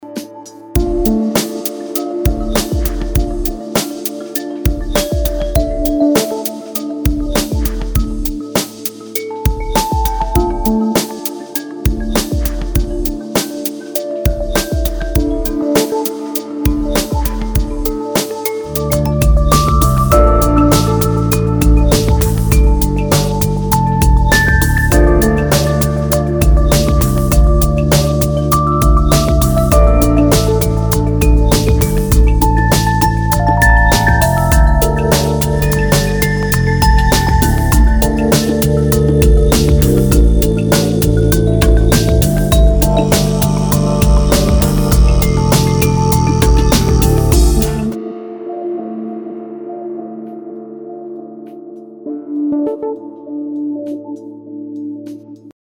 • Качество: 256, Stereo
спокойные